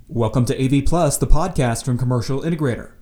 Below, you’ll find some audio samples recorded with three different microphones: the MXL Overstream bundle, a sub-$50 USB microphone made by a different manufacturer, and my iPhone 8’s voice recorder.
To keep these tests useful and consistent, I recorded them all in the same room and compressed the raw files into .MP3 format.
And here’s the MXL Overstream Bundle version:
Listening to the files on the same pair of headphones, it’s clear the MXL microphone sounds fuller and more detailed.